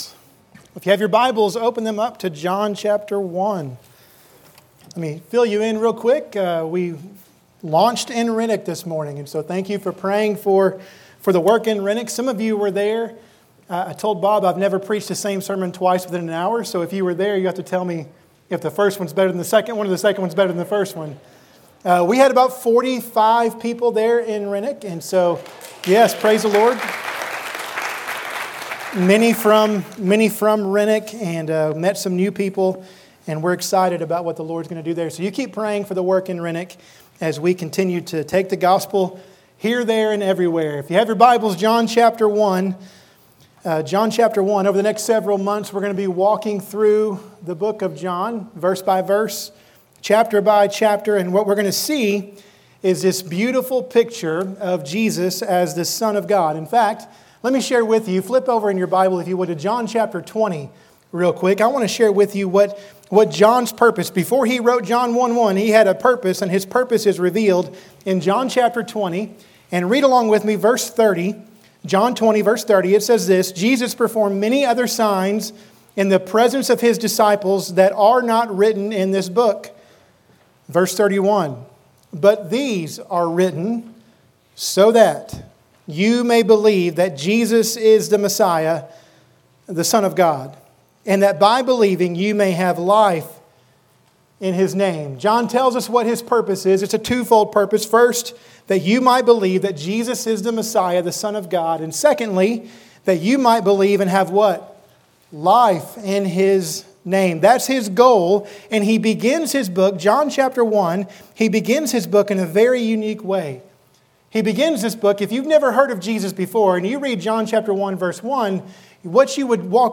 In this sermon we see four clear descriptions of Jesus, as John tells us who "The Word" is.